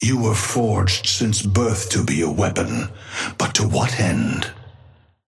Amber Hand voice line - You were forged since birth to be a weapon. But to what end?
Patron_male_ally_warden_start_02.mp3